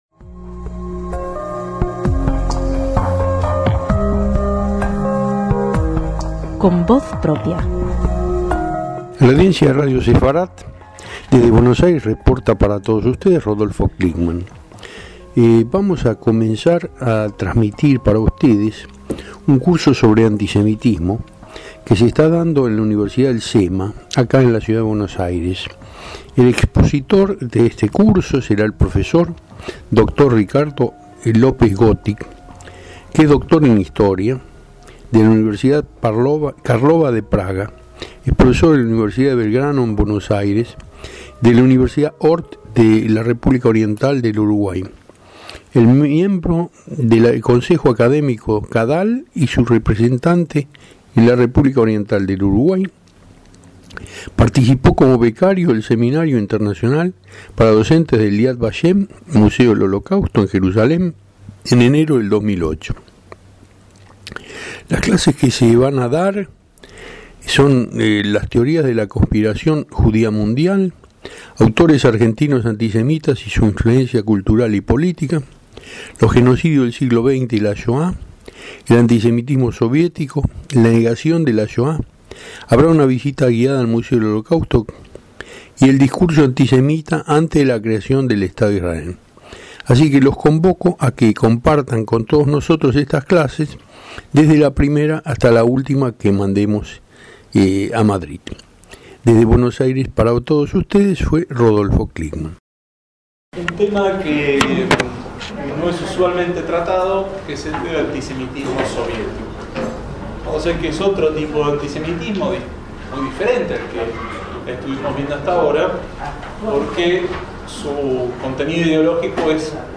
Esta conferencia pertenece al Seminario Historia del Antisemitismo, que el Programa Puente Democrático de CADAL ofreció en la Universidad del CEMA, de Buenos Aires.